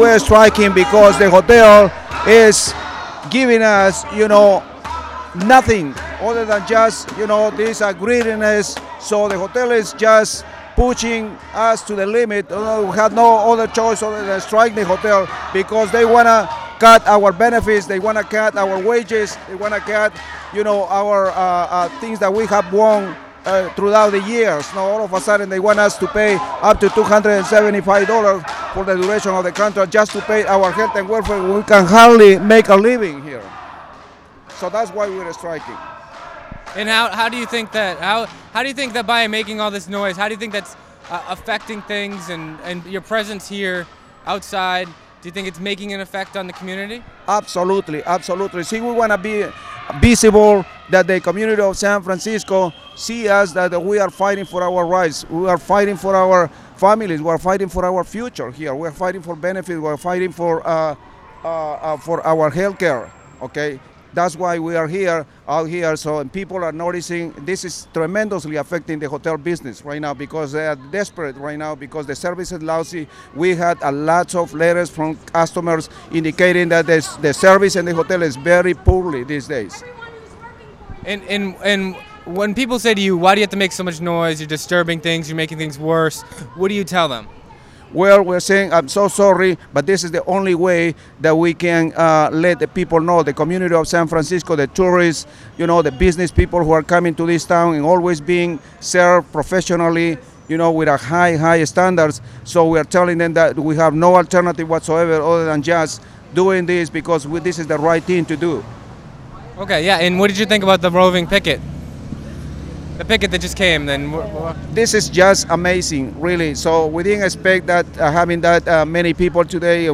Audio of Striking Hotel Worker from Sunday (Oct. 10th) night.
Some audio was captured of the evening -ambient noise and interviews. This audio is of one of the striking hotel workers.